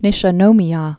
(nĭshə-nōmē-ä, nēshē-nômē-yä)